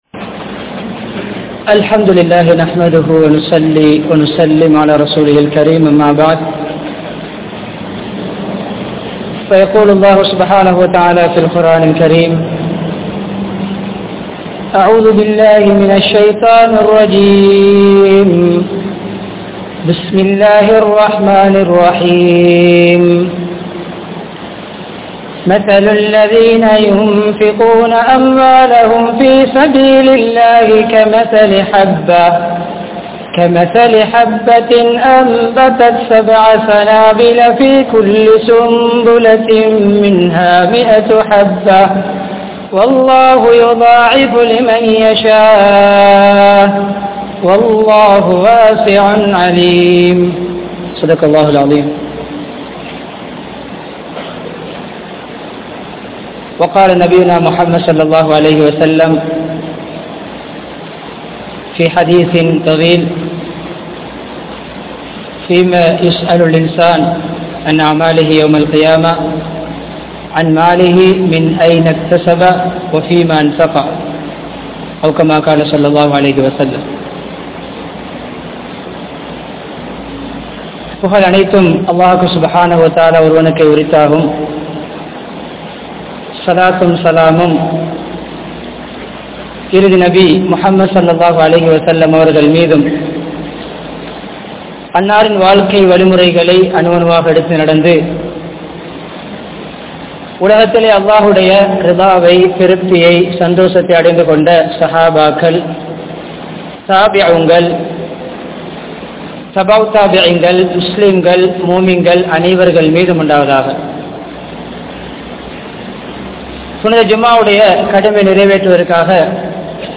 Selvaththai Marumaikkaaha Muthaleedu Seivoam (செல்வத்தை மறுமைக்காக முதலீடு செய்வோம்) | Audio Bayans | All Ceylon Muslim Youth Community | Addalaichenai
Kaluthura, Muhideen (Teru Palli) Jumua Masjith